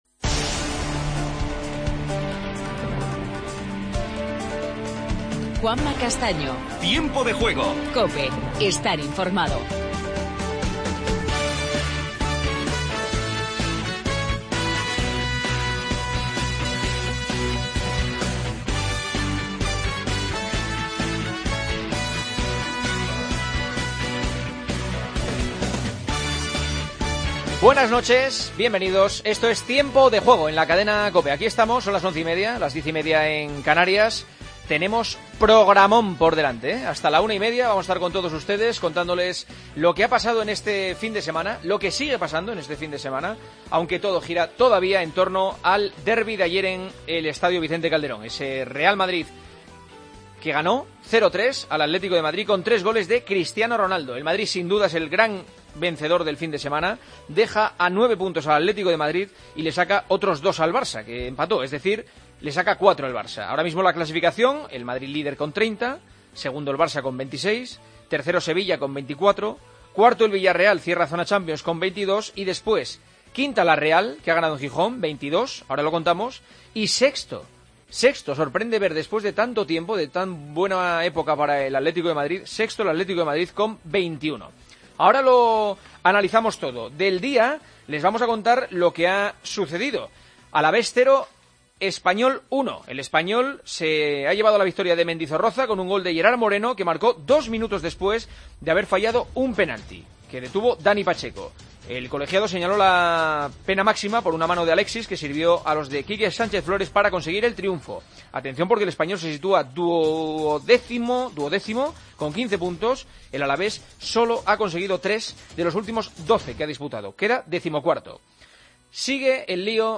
AUDIO: Titulares del día. Escuchamos a Kepa Arrizabalaga, guardameta del Athletic de Bilbao, y a Xabi Prieto, capitán de la Real Sociedad.